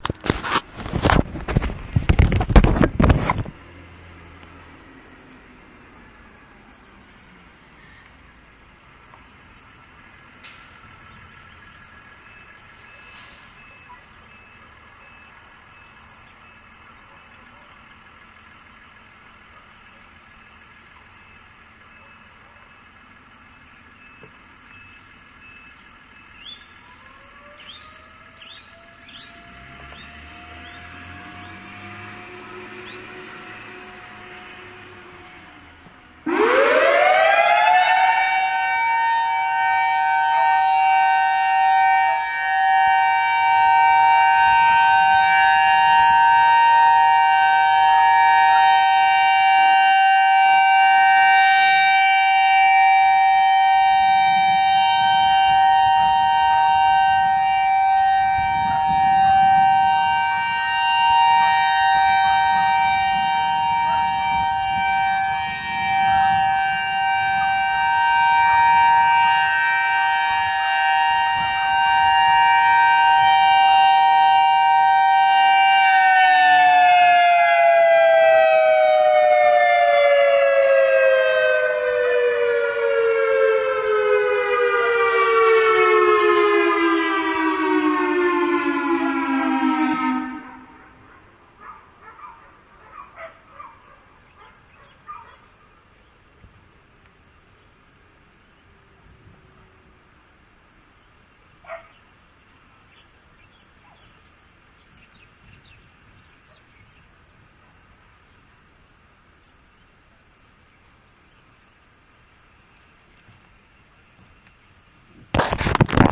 调制器音频无法使用 Mokuleia BP 3209
描述：夏威夷莫库莱亚2009年3月2日星期一上午11点45分，莫库莱亚海滩公园的调制器无法正常工作。你可以听到在我身后的莫库莱亚马球场，听起来像是另一个调制器。
标签： 民用 国防 夏威夷 故障 mokuleia 警报器 测试 警告
声道立体声